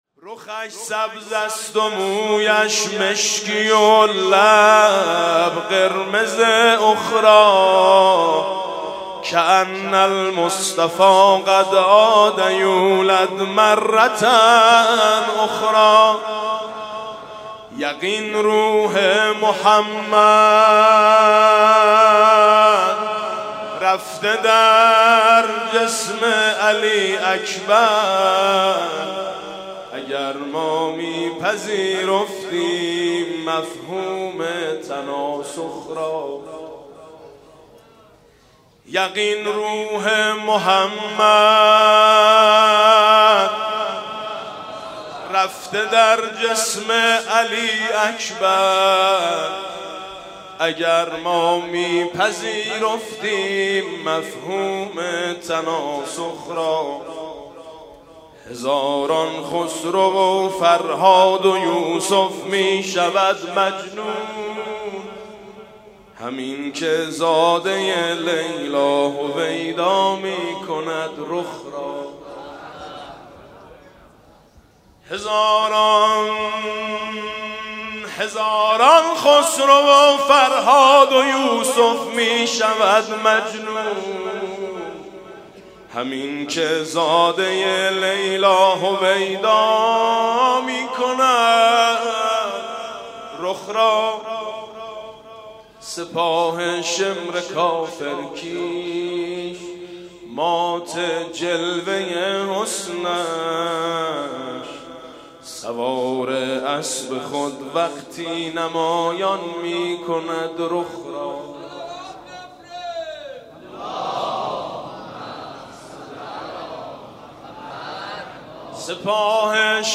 مدح: رُخش سبز است و مویش مشکی و لب قرمز اخری
مدح: رُخش سبز است و مویش مشکی و لب قرمز اخری خطیب: حاج میثم مطیعی مدت زمان: 00:03:09